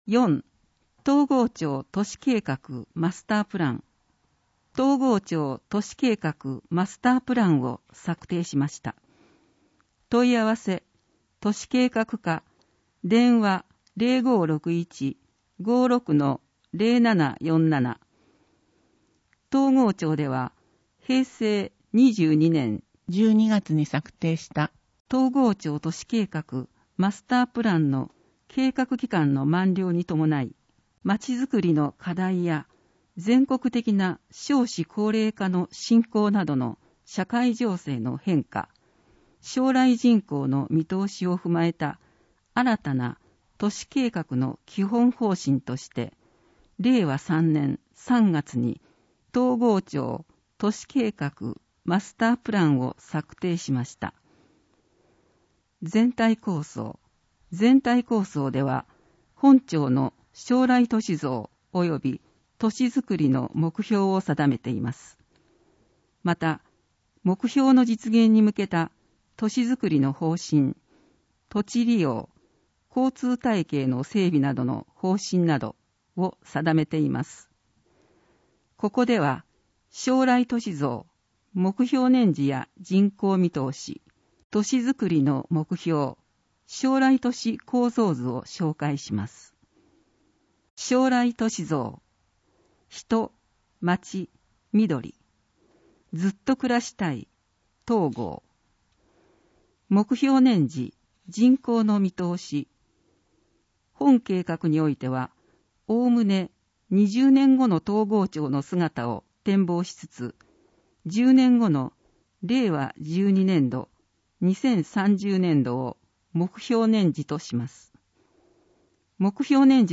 広報とうごう音訳版（2021年5月号）